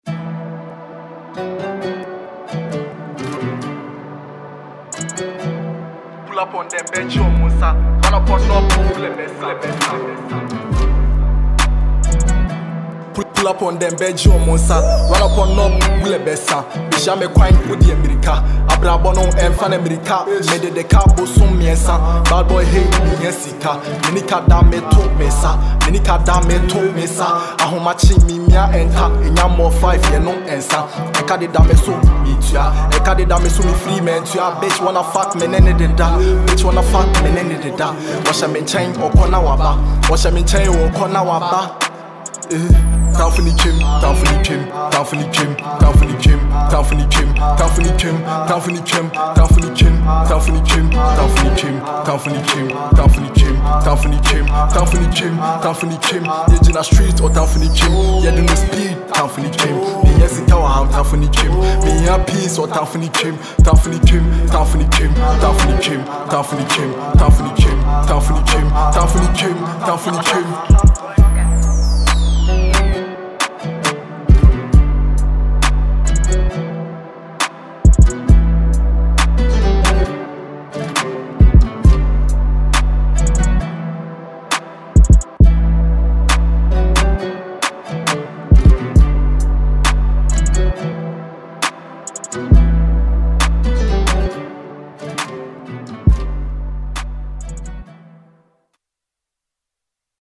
asakaa trapper
This is a banger all day.